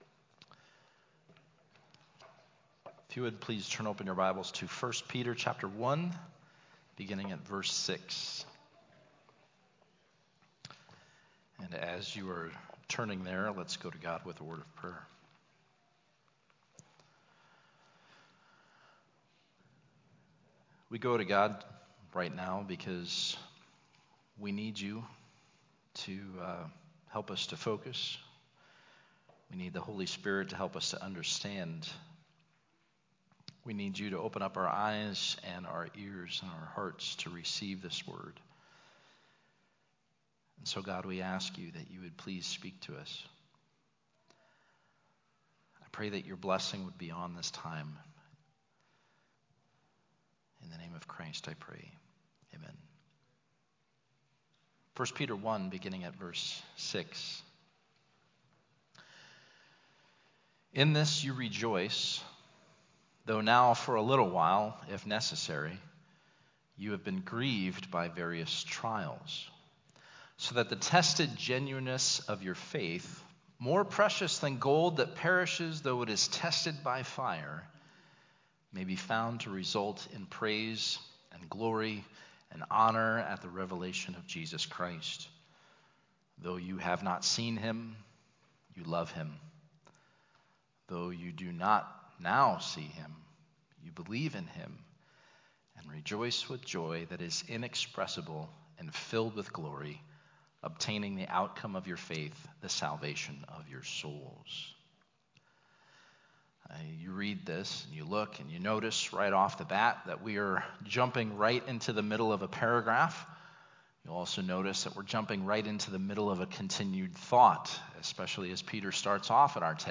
Suffering is a difficult subject to discuss, and a difficult thing to endure. But the Biblical perspective of suffering teaches us that it is for a purpose, and that it proves the genuineness of our faith. The text for this message is 1 Peter 1:3-6.